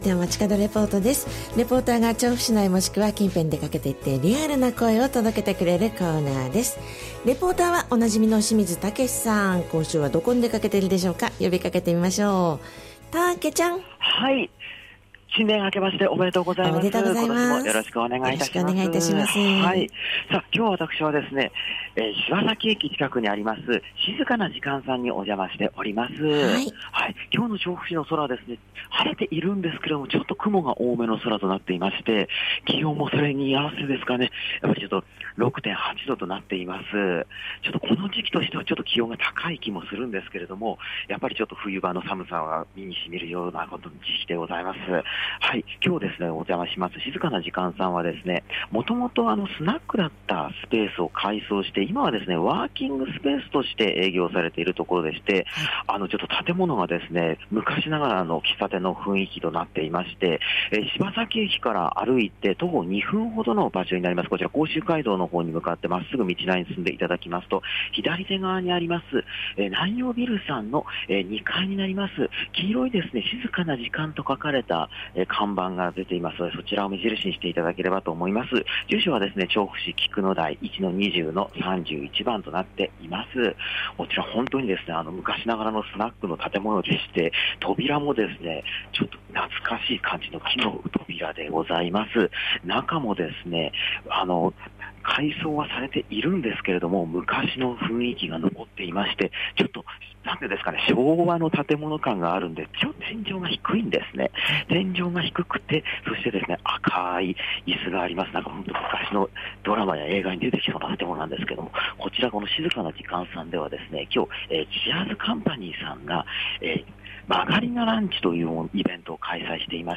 今年最初のたまにち街角レポートは、
柴崎のワーキングスペース『静かなじかん』さんで開催されている間借りなランチから！